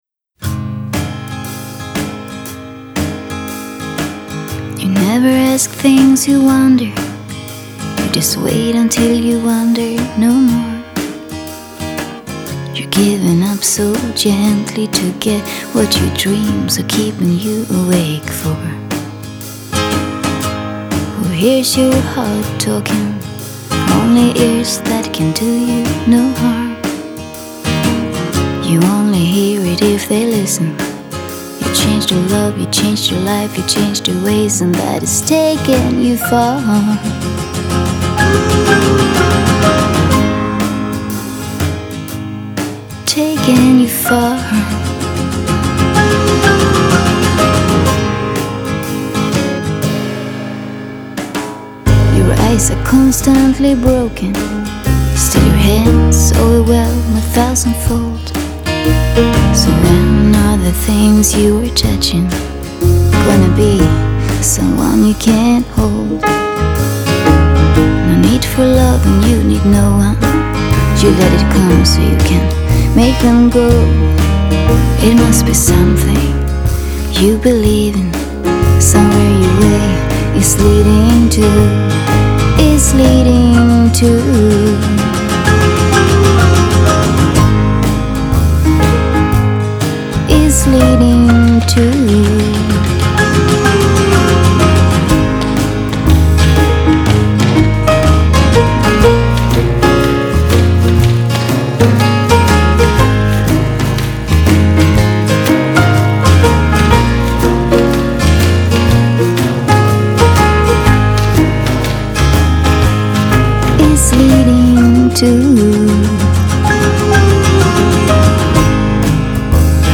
장르: Jazz, Pop
스타일: Vocal, Ballad